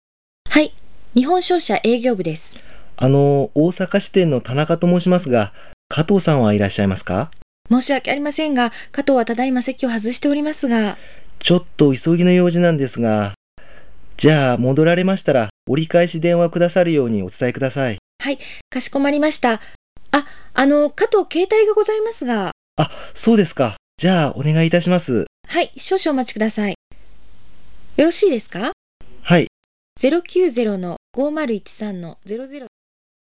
：　対話の部分（電話のベル音を貼付け。
また電話なのでイコライザ処理を加える）
・イコライザー利用（対象：Dialogue.wavの男性の声）
Dialogue.wav